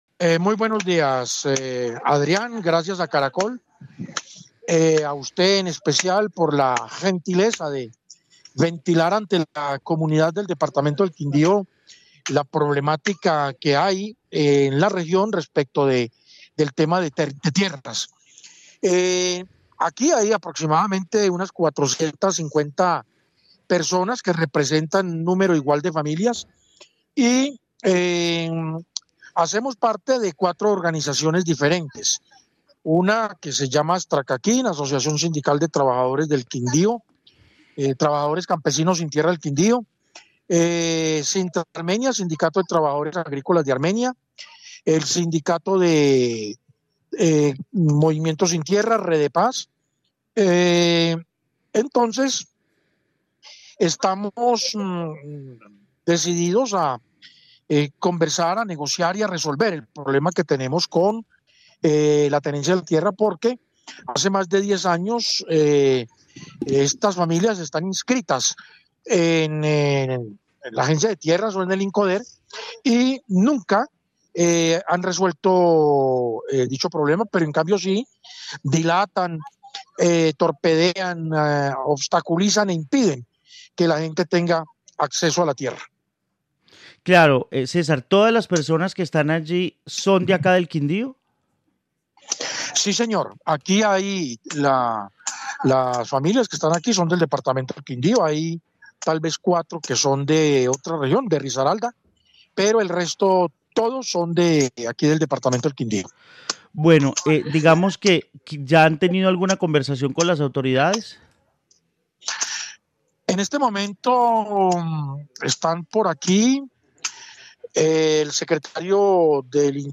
voceros de los campesinos